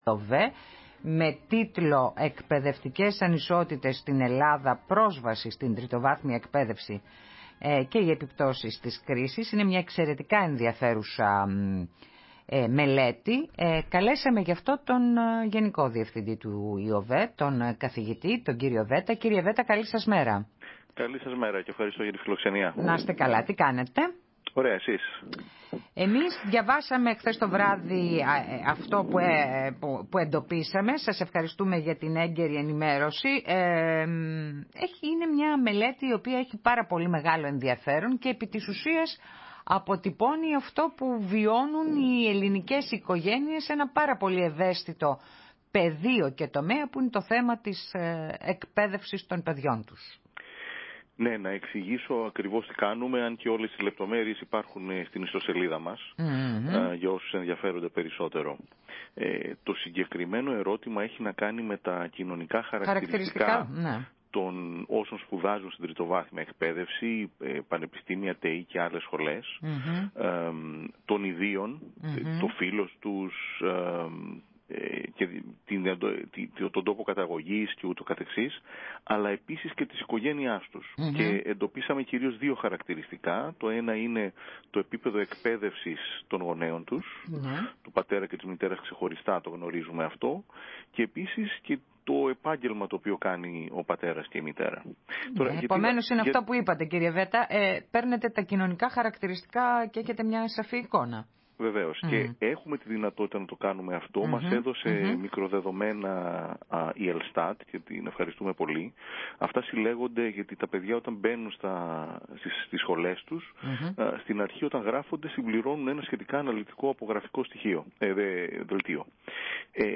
Συνέντευξη
στη ραδιοφωνική εκπομπή «Ναι μεν, αλλά»